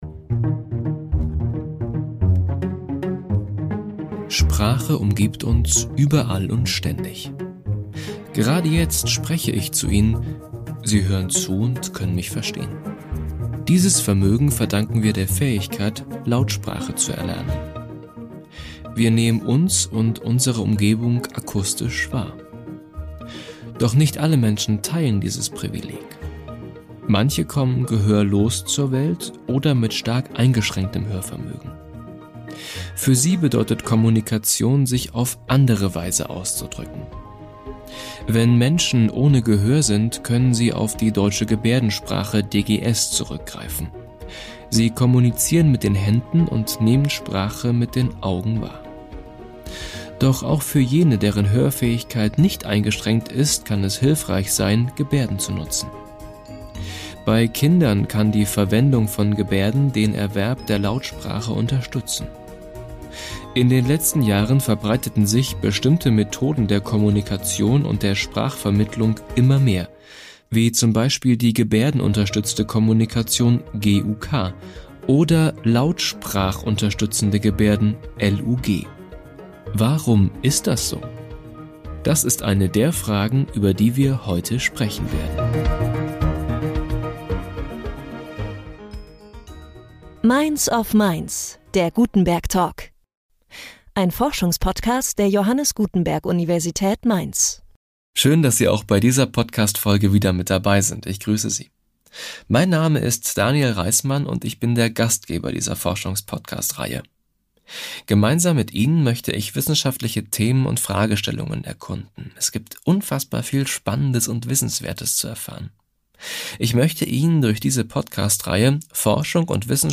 Unter anderem darüber geht es im Gespräch